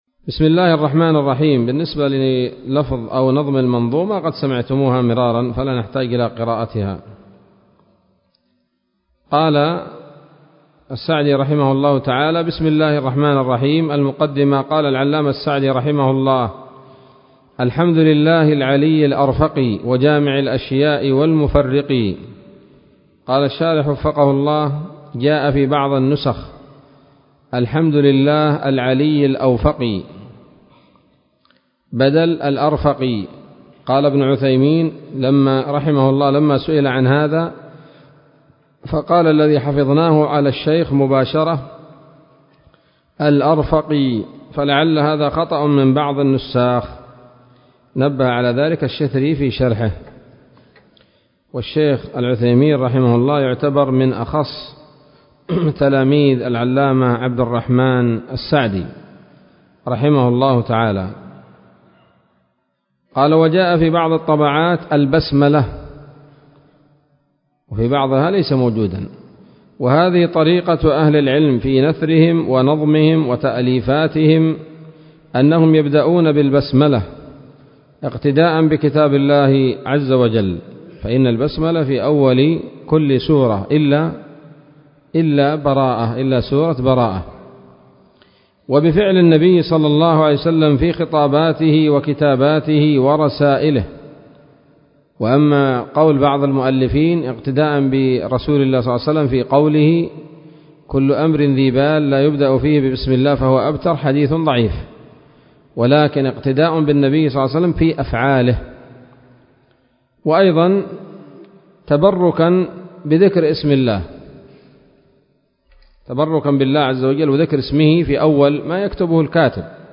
الدرس الخامس من الحلل البهية في شرح منظومة القواعد الفقهية